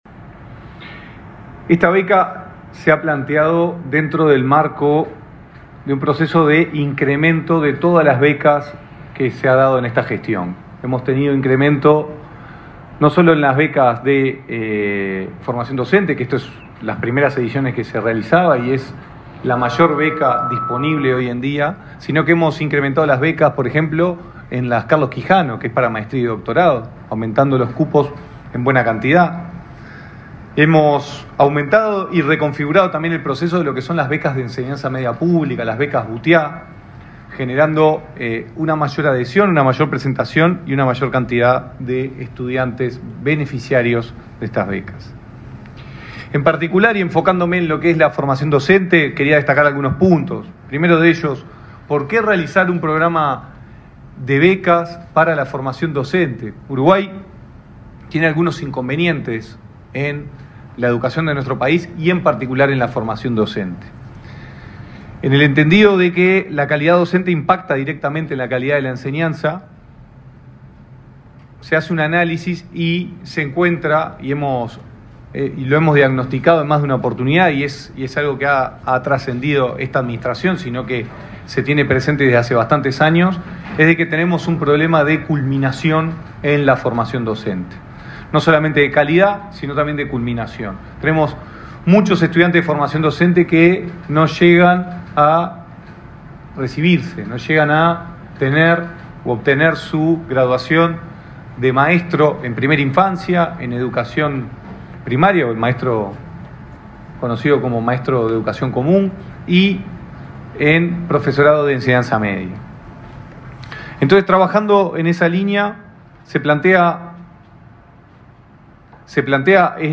Palabra de autoridades del MEC 19/12/2024 Compartir Facebook X Copiar enlace WhatsApp LinkedIn El director nacional de Educación, Gonzalo Baraoni, y el titular del Ministerio de Educación y Cultura (MEC), Pablo da Silveira, participaron en el lanzamiento del programa Beca Docente Acreditado 2025.